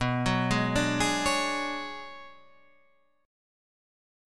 B Minor 6th Add 9th